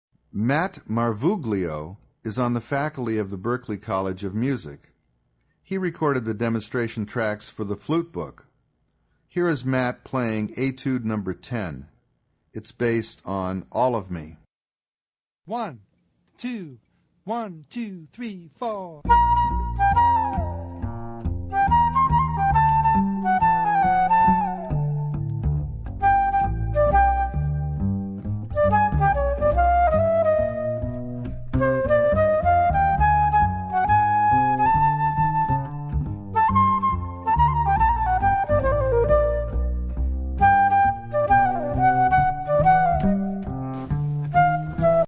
Obsazení: Flöte